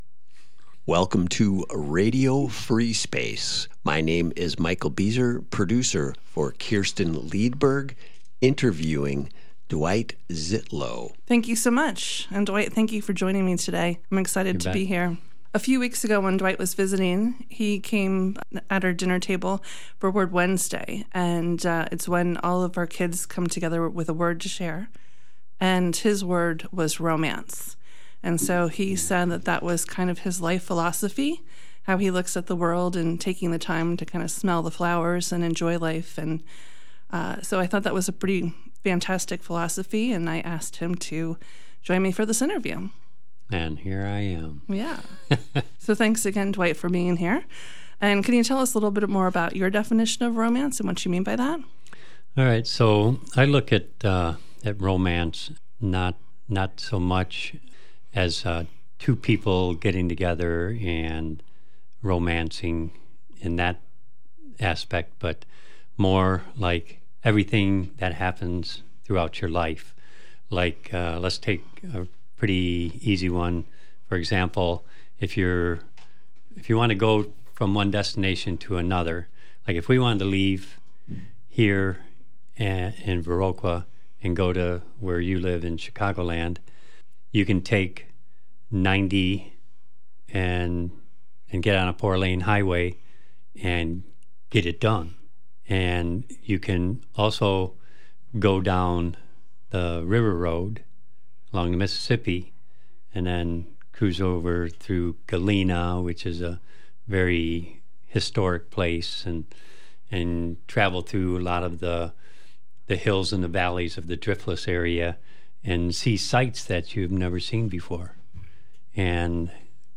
Part one of an interview with local legend and all around great guy